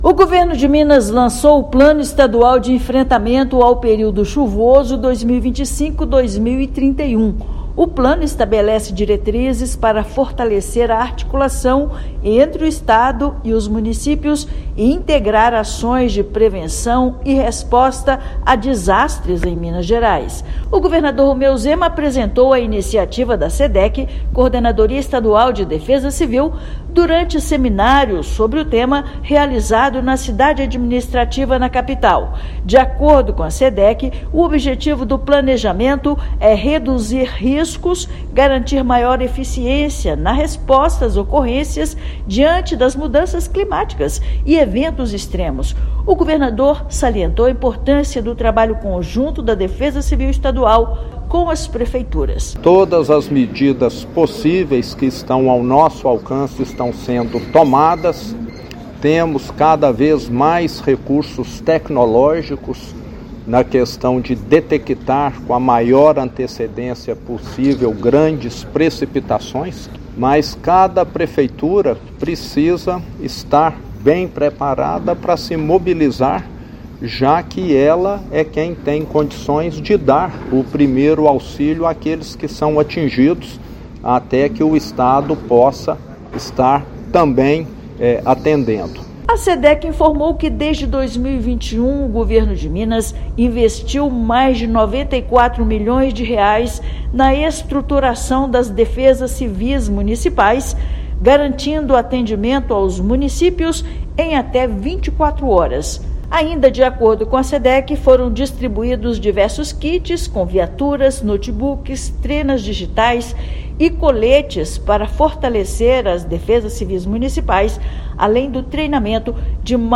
Projeto da Defesa Civil estadual fortalece a prevenção e preparação para ocorrências e a articulação com instituições e municípios. Ouça matéria de rádio.